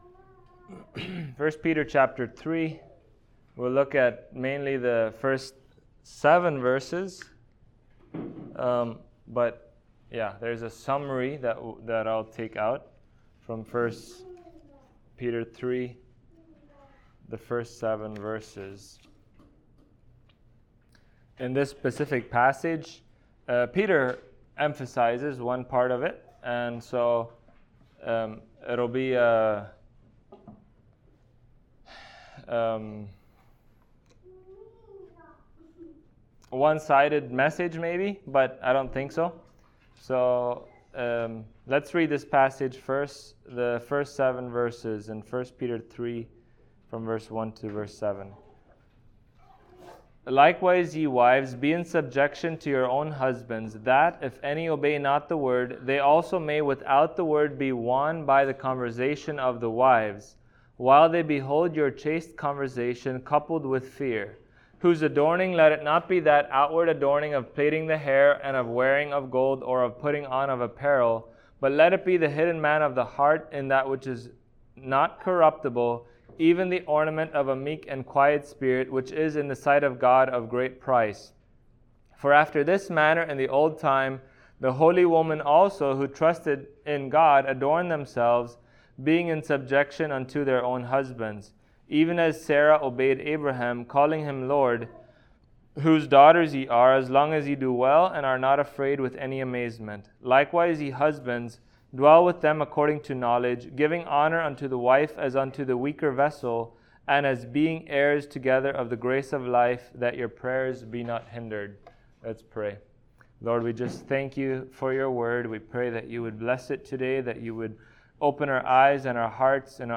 1 Peter Passage: 1 Peter 3:1-7 Service Type: Sunday Morning Topics